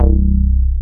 BAS_ShameBass1.wav